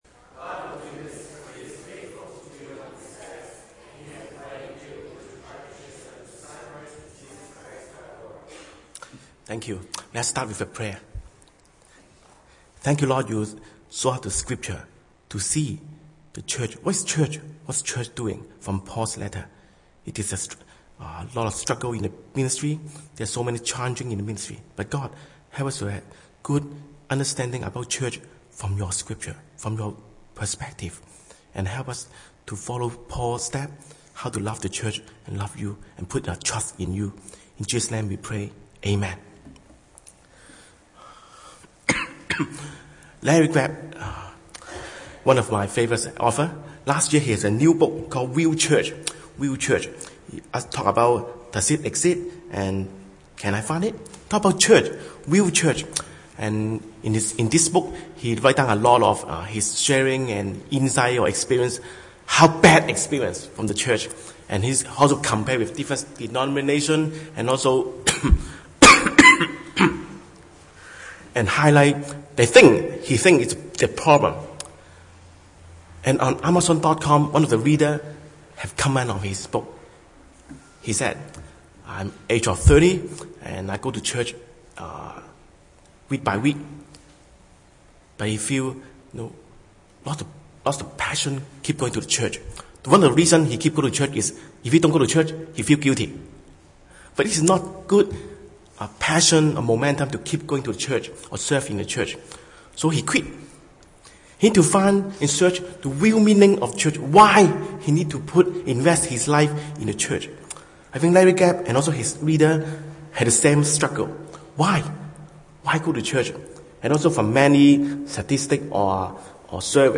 Preacher